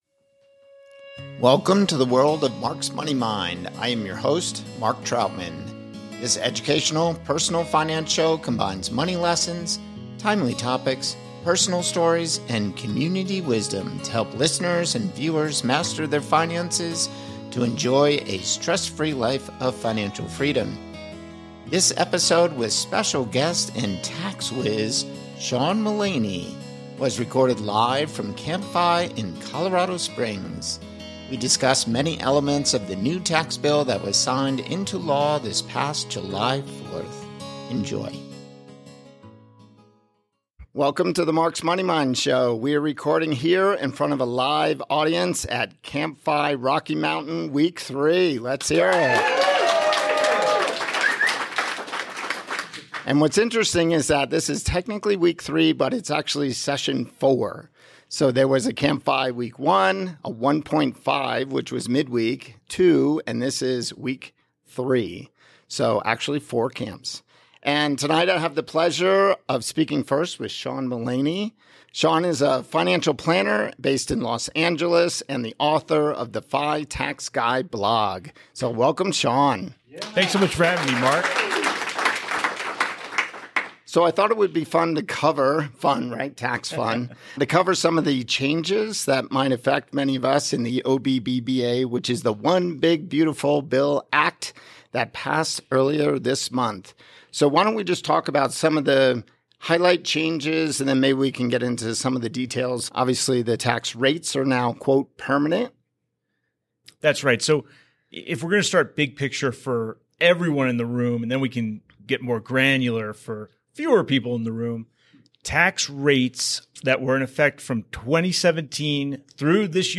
CPA live from CampFI in Colorado Springs. They dive into the recent changes brought by the new tax legislation, the One Big Beautiful Bill Act (OBBBA), signed into law on July 4th. Key topics include permanent tax rates, increased standard deductions, higher child tax credit, new personal exemptions for seniors, potential future tax adjustments, and expanded eligibility for HSA contributions under ACA plans.&nbsp